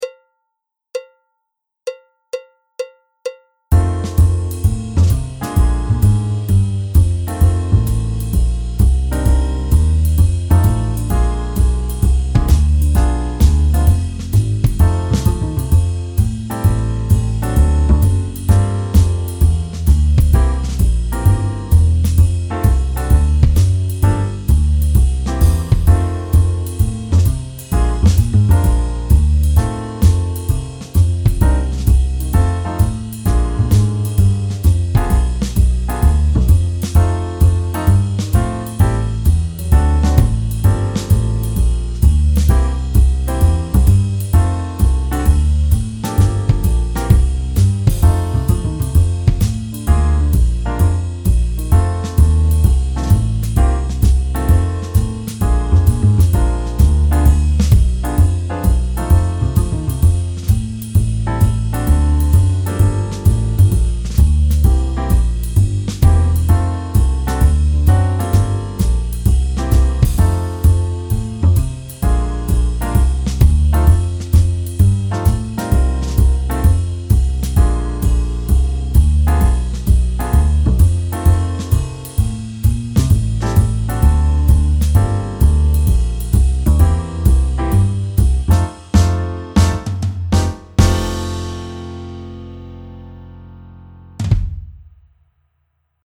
ジャズ／ポップ＆ロックミュージックコース 課題曲　リズムトラック・カラオケ
大学・短大ジャズコース